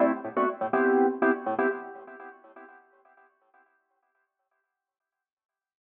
08 ElPiano End Riff + Tail.wav